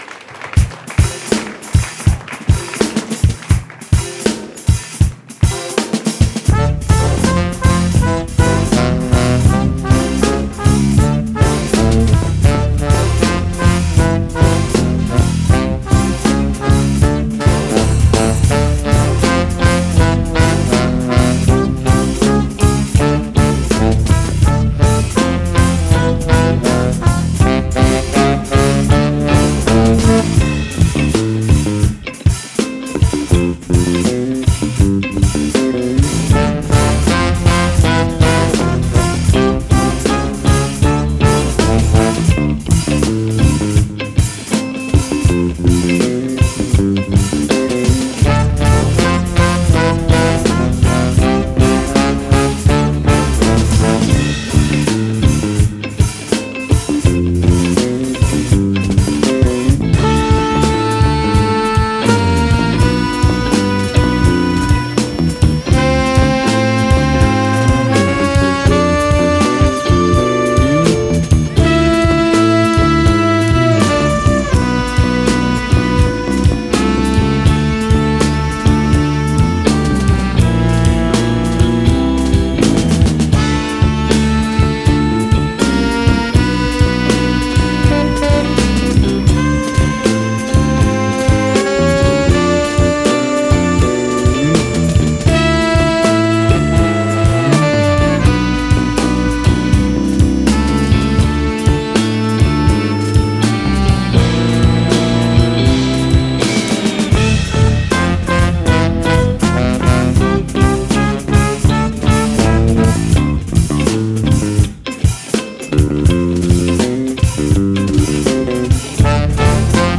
drums
bass
reeds
trumpet / electronics
guitar